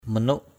/mə-nuʔ/